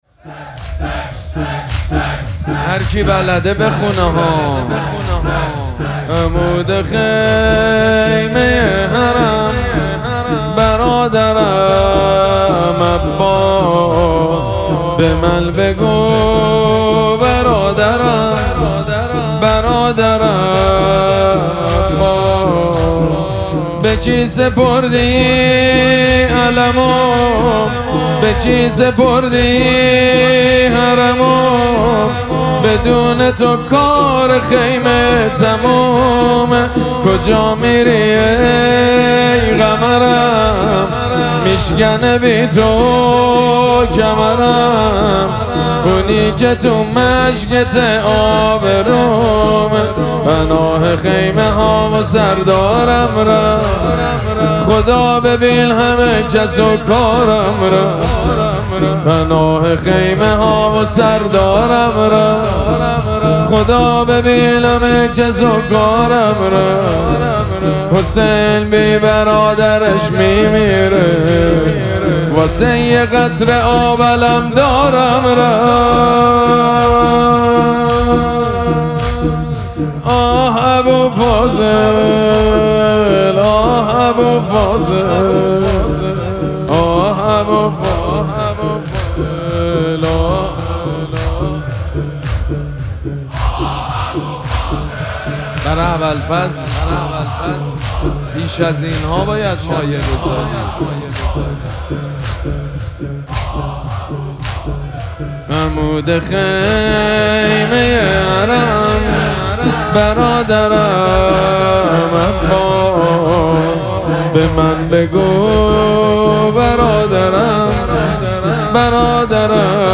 مراسم عزاداری وفات حضرت ام‌البنین سلام‌الله‌علیها
مداح
حاج سید مجید بنی فاطمه